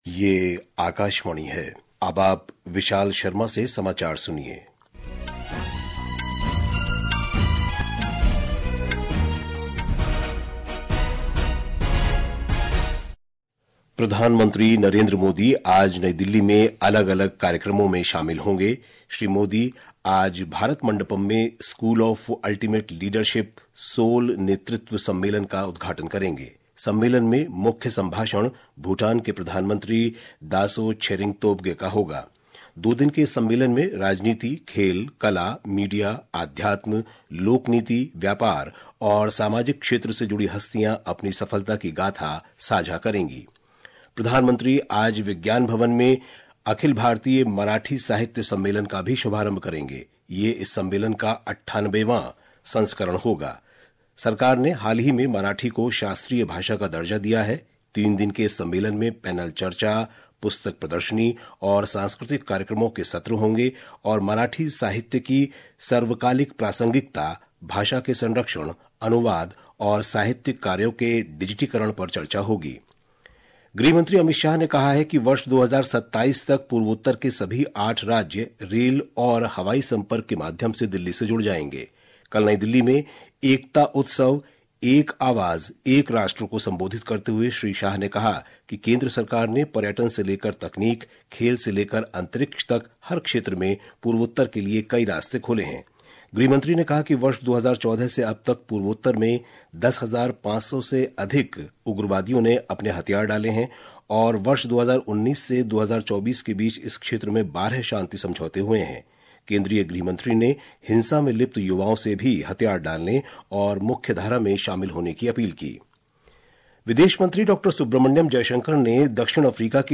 प्रति घंटा समाचार